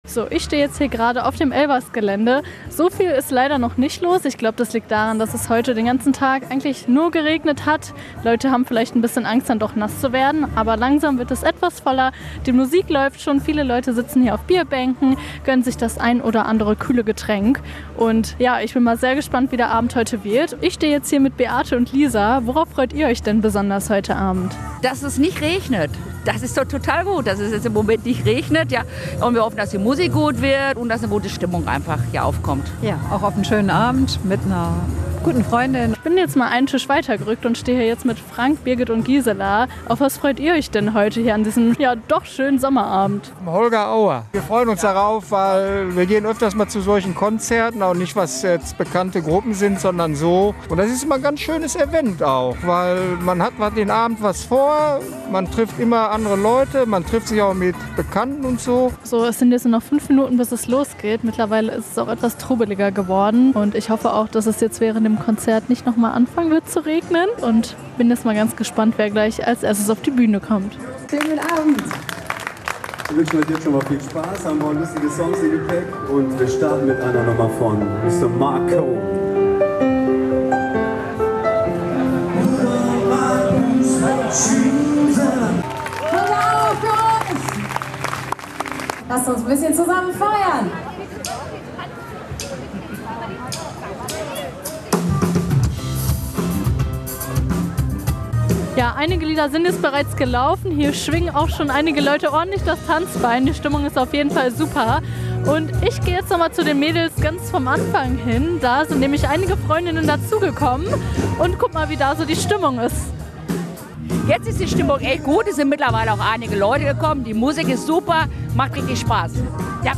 Unter dem Motto "ELBERS Live!" wurde gestern auf dem Elbersgelände das Tanzbein geschwungen. Die Veranstaltung in der Hagener Innenstadt bot Live-Musik, Drinks und Snacks.